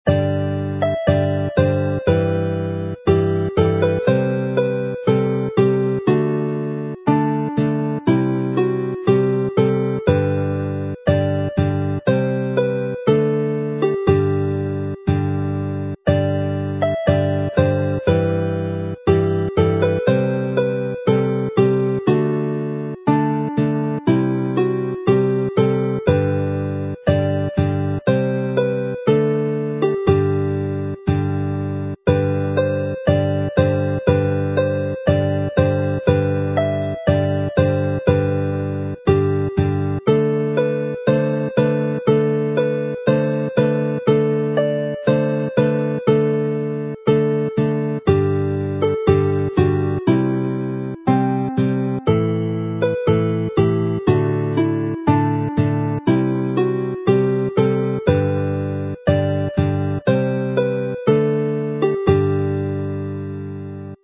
mp3 file, slow with chords